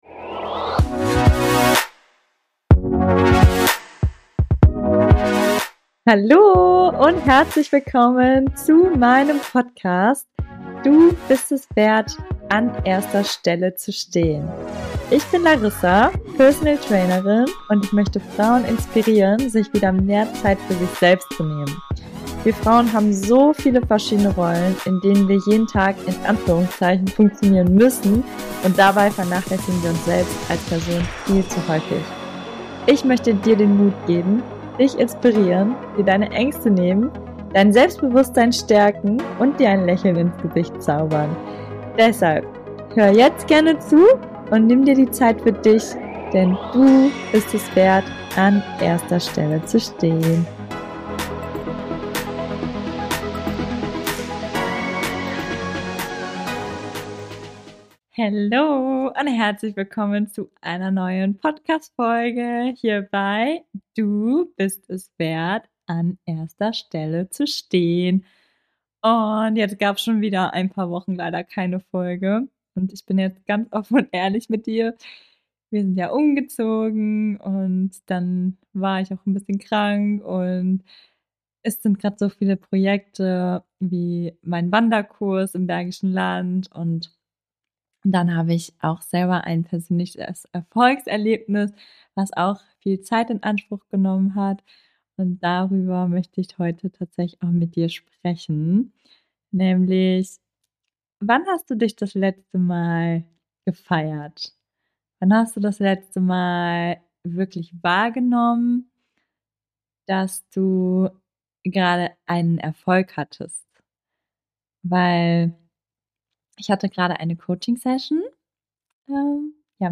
Ich rede heute davon, warum es so wichtig ist, dass du dich feierst und was es dir positives bringt. Und ich teile mit dir meinen letzten Erfolg - natürlich mal wieder ganz ohne Skript und offen und ehrlich mit allem, was mir gerade dazu einfällt.